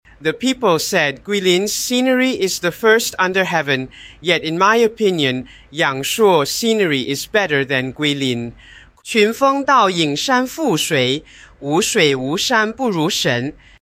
ElevenLabs_Mandarin-English.mp3